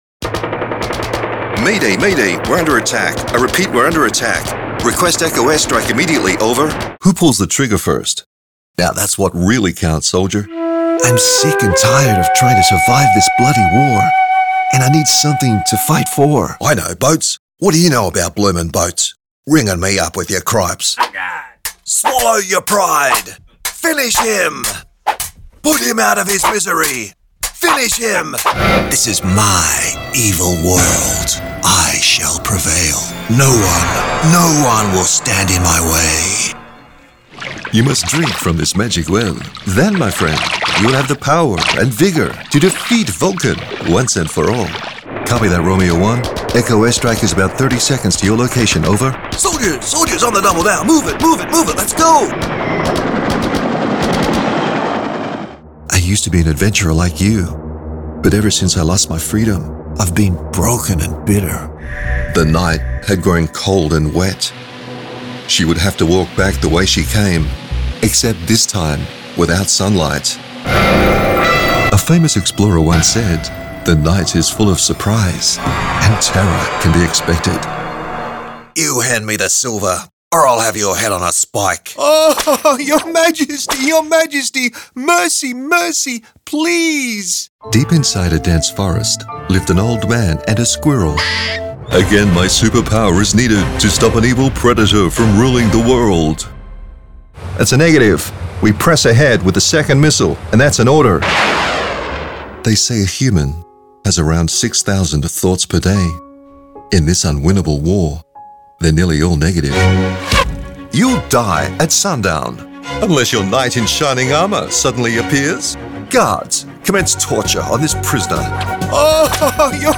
Spanish (Latin American)
Adult (30-50) With a broadcast background
0504GAMING_MONTAGE_AMPED.mp3